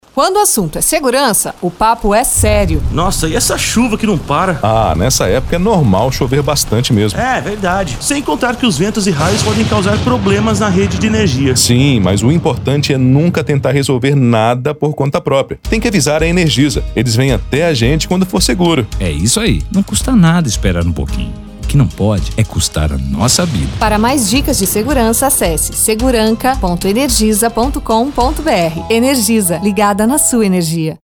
peca_7_spot_radio.mp3